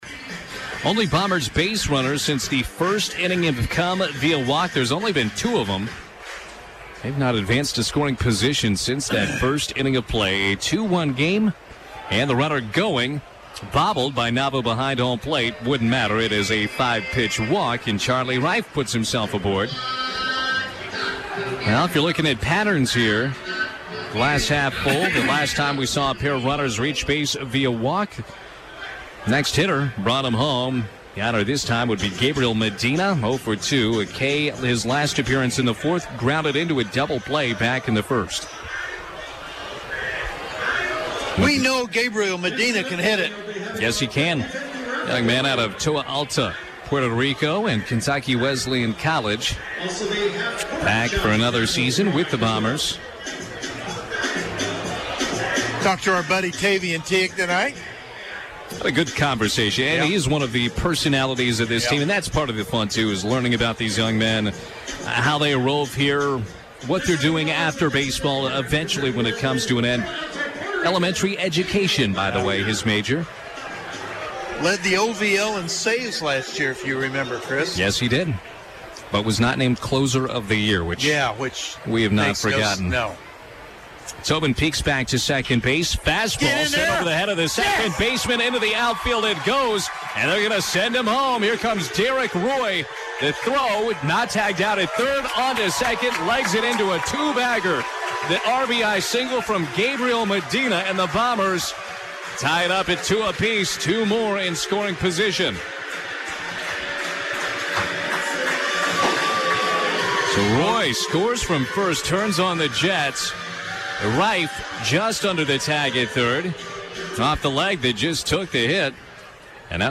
Best Radio Local Sports Play-by-Play CoverageWITZ-FM (Jasper) – Bombers Rally for Late Lead
Best locally originated play-by-play coverage of high school sports broadcast.